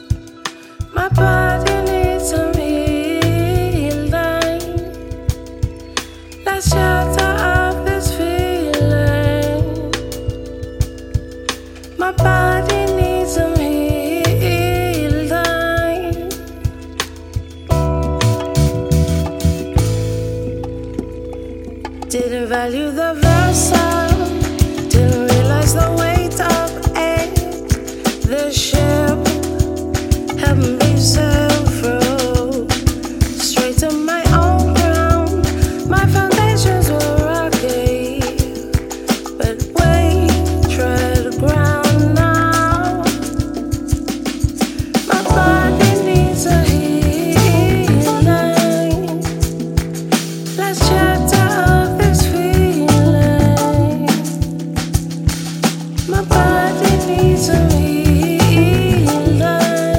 uniquely laidback soulful style
a glorious fusion of neo-soul and jazz
Soul